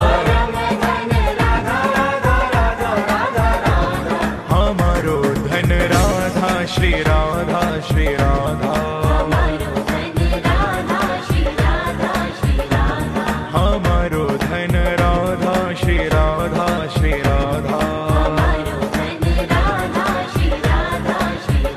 Category Devotional